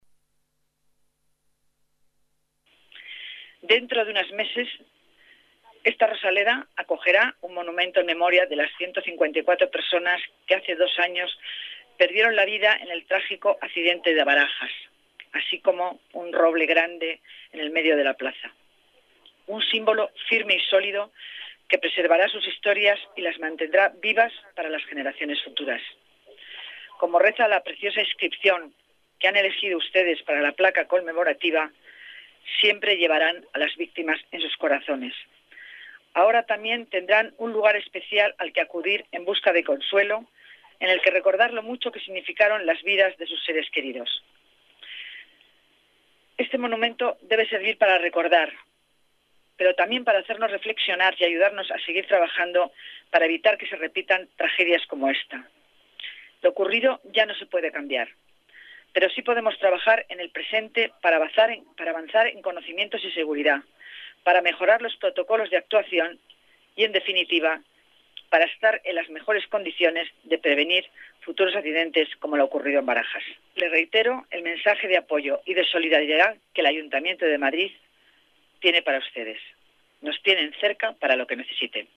Dancausa asiste al acto en memoria de las 154 víctimas al cumplirse dos años de la tragedia
Nueva ventana:Declaraciones de la delegada de Familia y Servicios Sociales, Concepción Dancausa: Monumento accidente Spanair